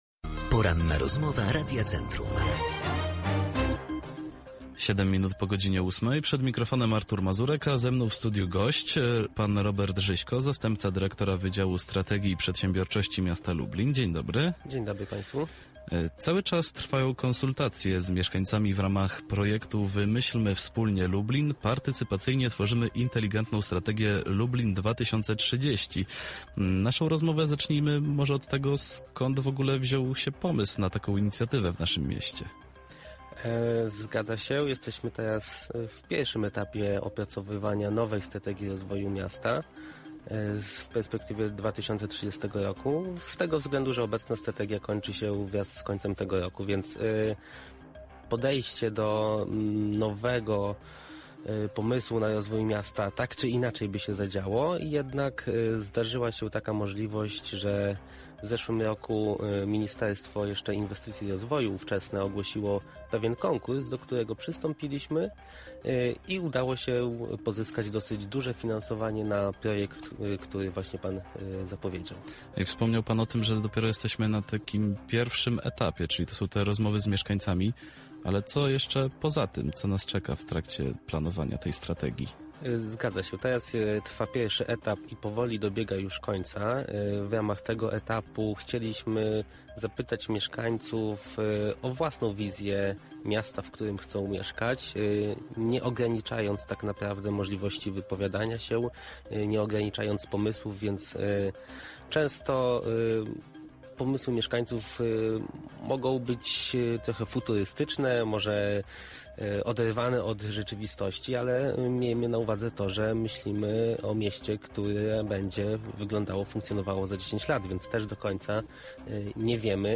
Pełen zapis Porannej Rozmowy Radia Centrum znajdziecie poniżej: